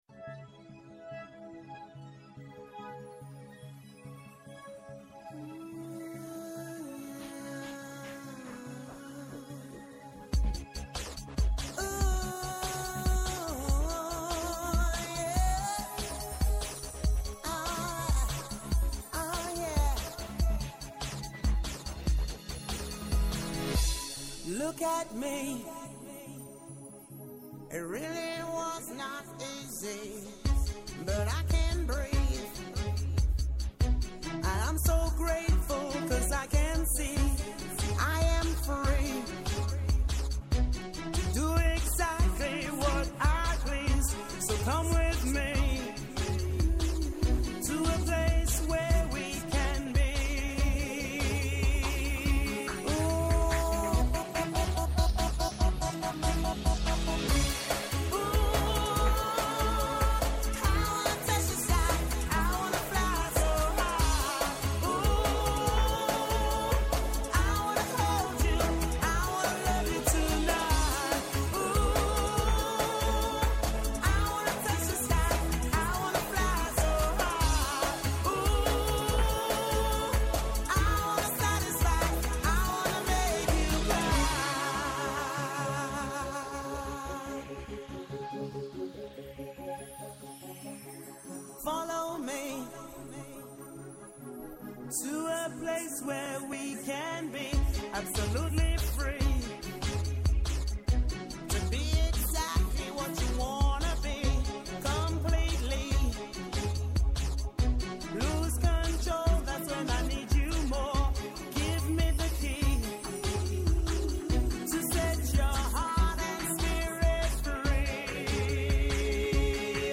Κάθε Παρασκευή 10 με 11 το πρωί και κάθε Σάββατο 10 το πρωί με 12 το μεσημέρι στο Πρώτο Πρόγραμμα της Ελληνικής Ραδιοφωνίας.